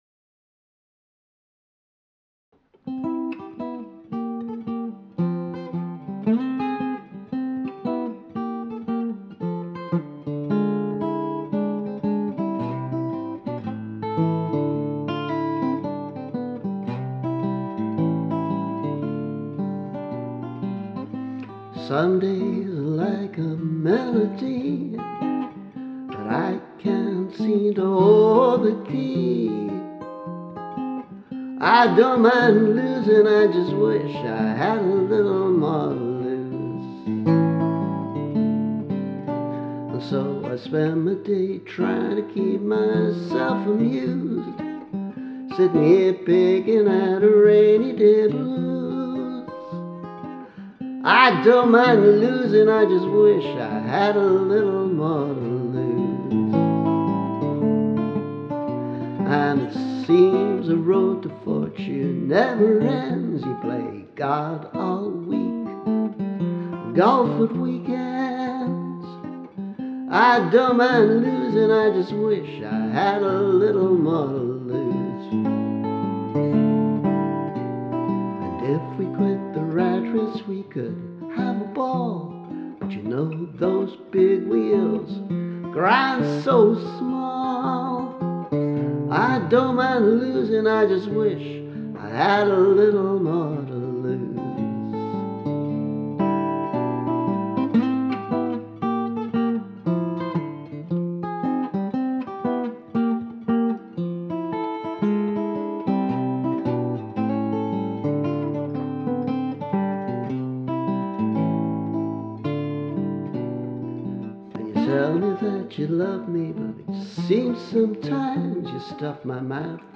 A rainy day blues [demo]